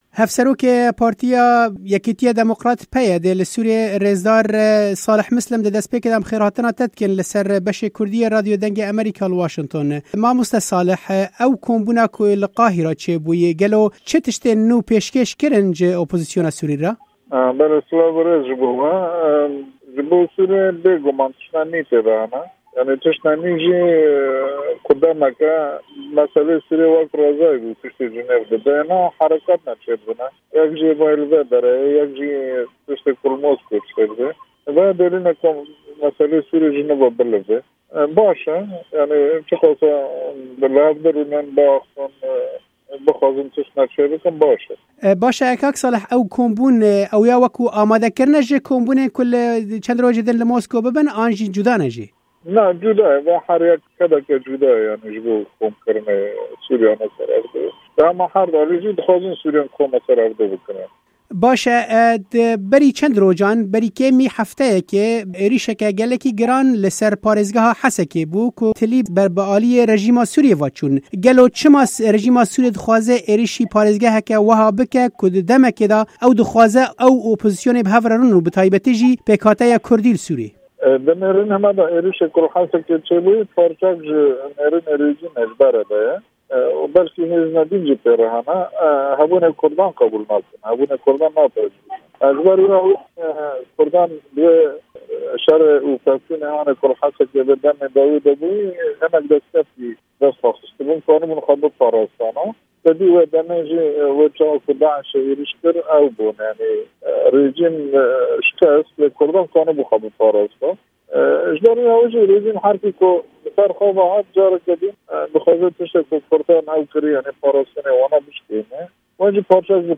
Di hevpeyvîna Dengê Amerîka de Hevserokê PYD'ê Salih Mislim Konferansa Qahîrê dinirxîne û dibêje, di vê demê de ku hewlên kêşeya Sûrî cemidîne, ew konferans hewleke girîng e.